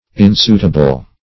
Search Result for " insuitable" : The Collaborative International Dictionary of English v.0.48: Insuitable \In*suit"a*ble\, a. Unsuitable.